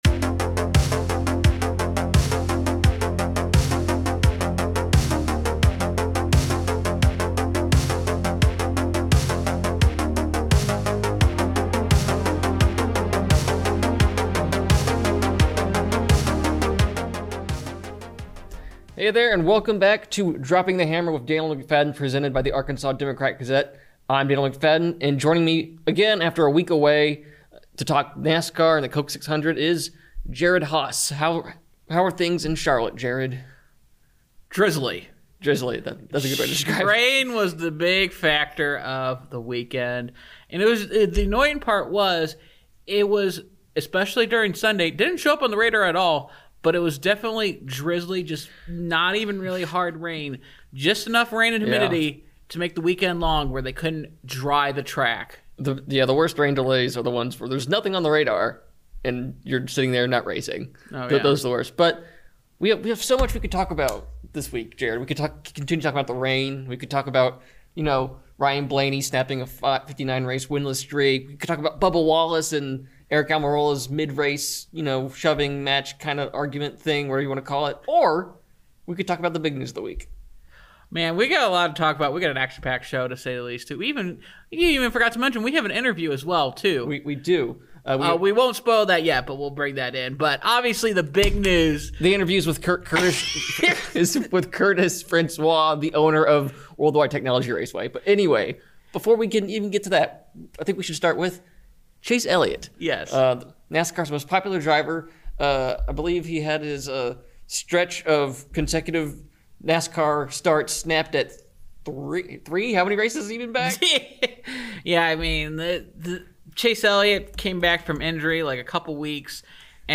Charlotte’s back, baby: Coke 600 recap + interview